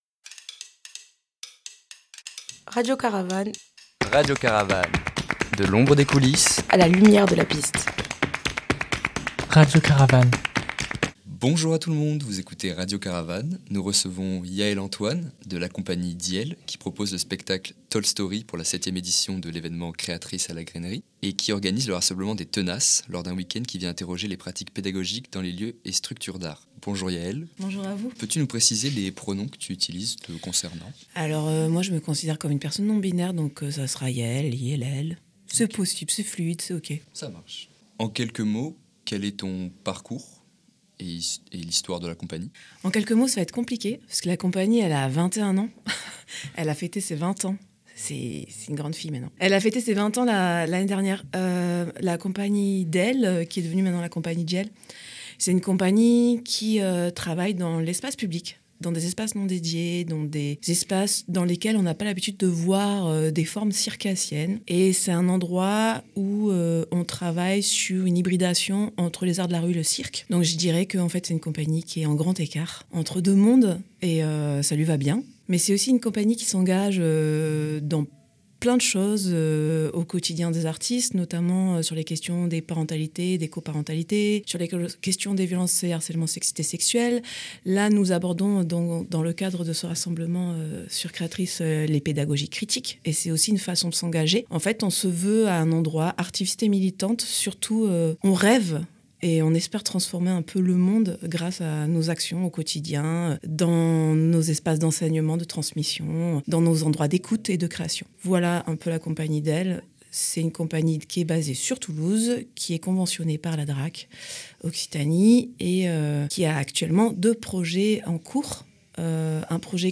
RC_2025_CREATRICES_itw Cie d'Ielles.mp3